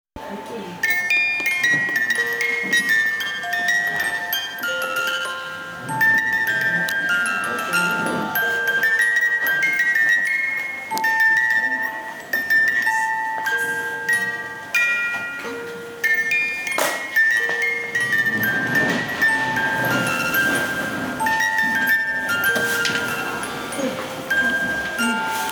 web授業参観（校歌オルゴール音声付き）